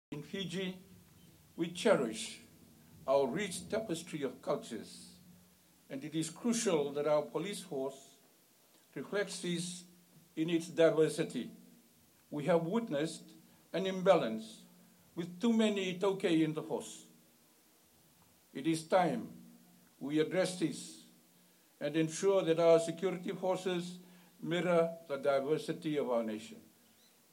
This point was underscored in his speech during the Police Pass Out parade at the Fiji Police Academy grounds yesterday.